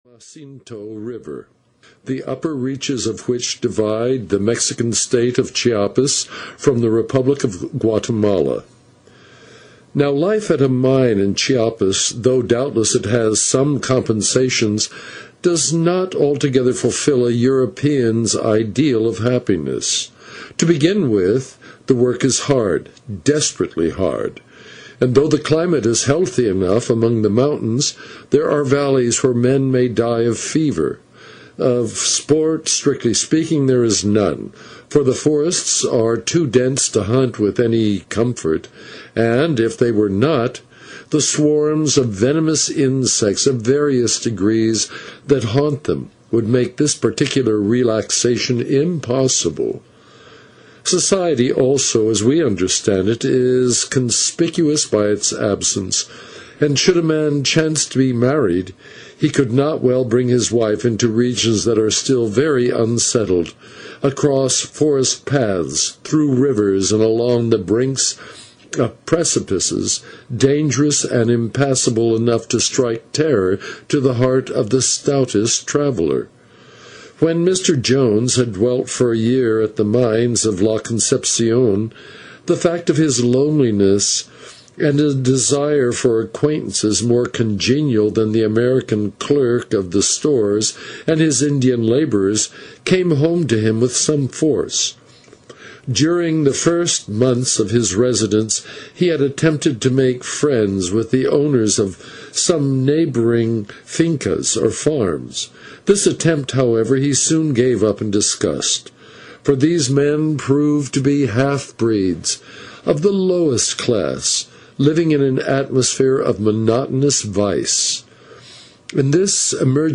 Heart of the World (EN) audiokniha